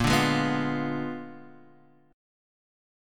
A#7b9 chord